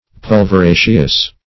Search Result for " pulveraceous" : The Collaborative International Dictionary of English v.0.48: Pulveraceous \Pul`ver*a"ceous\, a. (Bot.)
pulveraceous.mp3